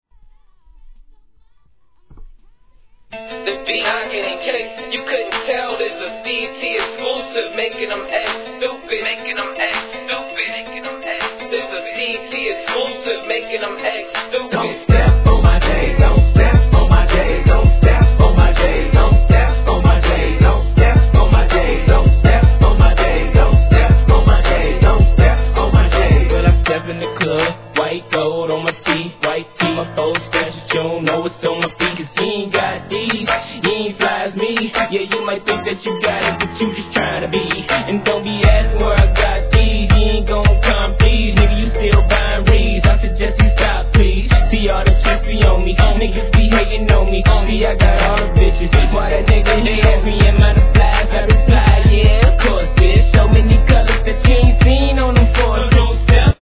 HIP HOP/R&B
両面共にDEEPなサウスサウンドに好き者なら必ず中毒に★